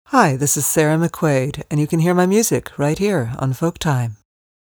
In-Stream recorded "tags": Record us a 5 second tag that we'll play ahead of each of your songs.